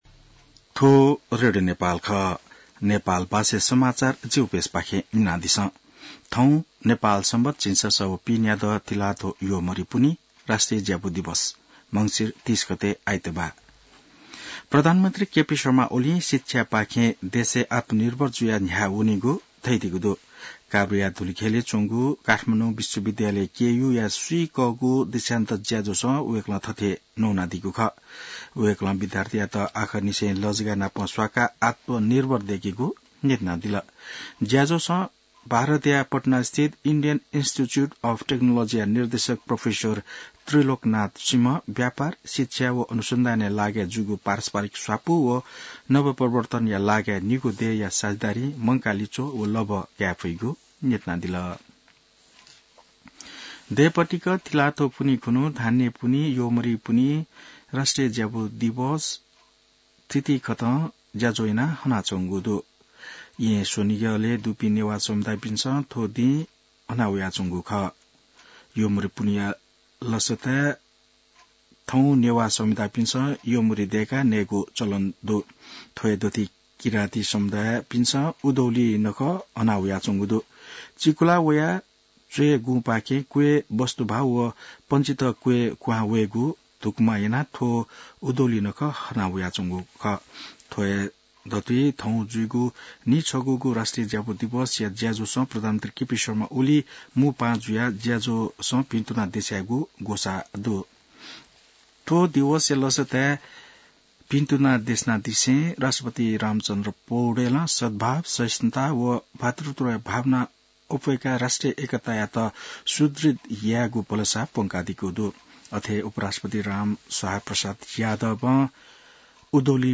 नेपाल भाषामा समाचार : १ पुष , २०८१